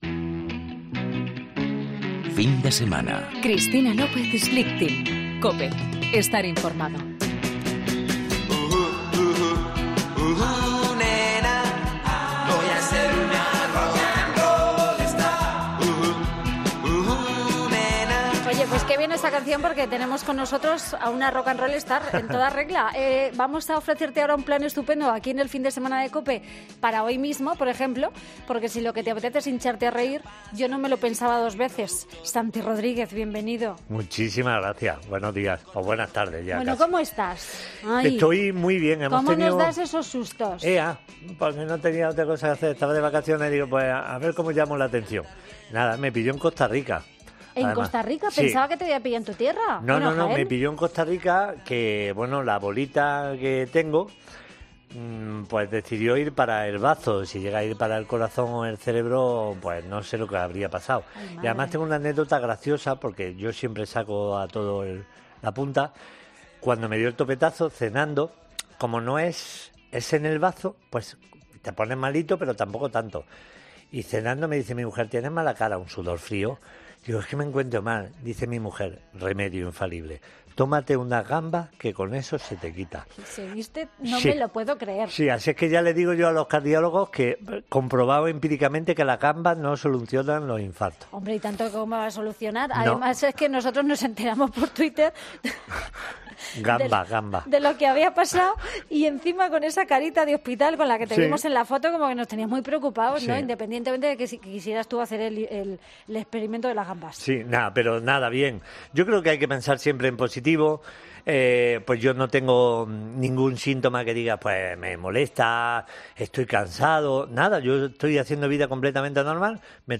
Escucha la entrevista al cómico Santi Rodrígez en 'Fin de Semana'